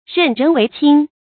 任人唯亲发音
成语正音唯，不能读作“wēi”。